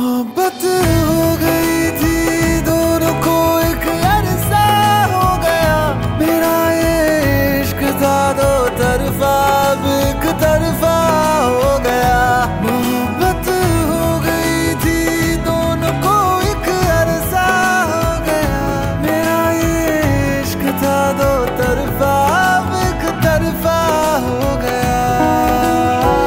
Bollywood Ringtones